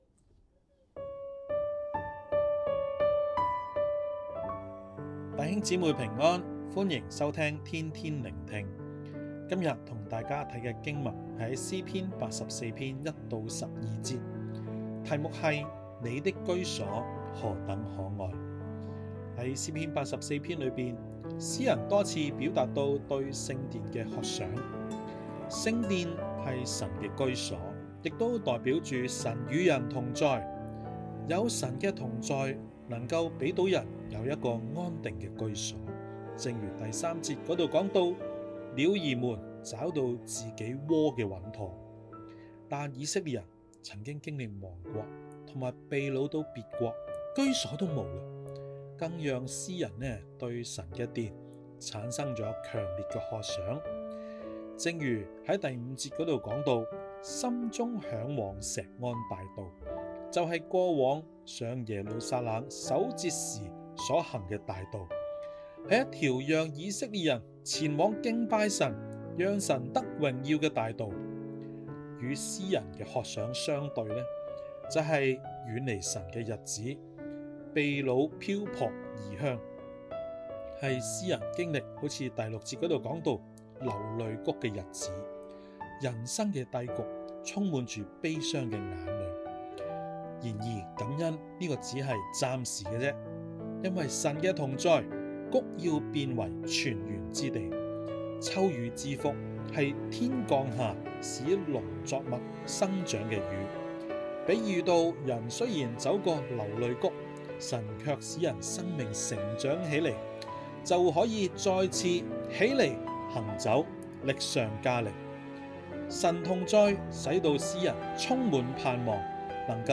粵語錄音連結🔈